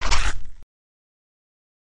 Bite
Tags: Halloween sounds halloween mix halloween scary sounds Monster laugh dracula tree falling sound